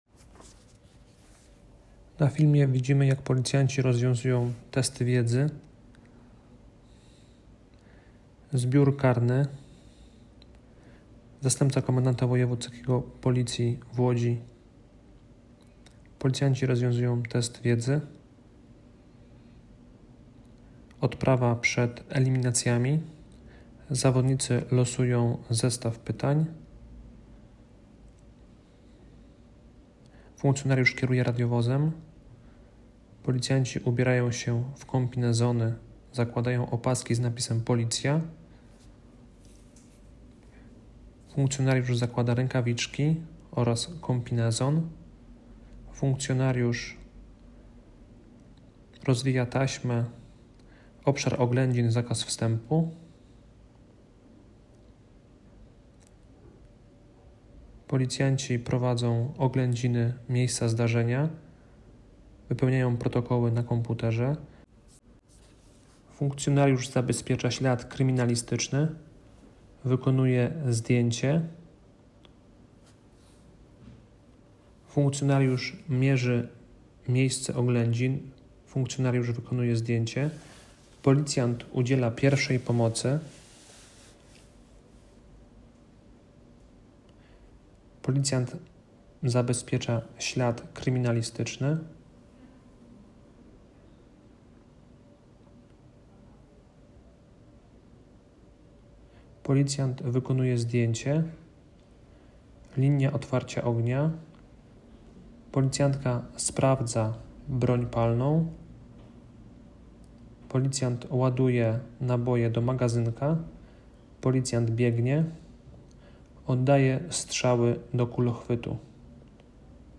Nagranie audio Audiodeskrypcja_1.m4a